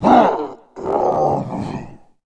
Sound / sound / monster / recycle_monster / dead_1.wav
dead_1.wav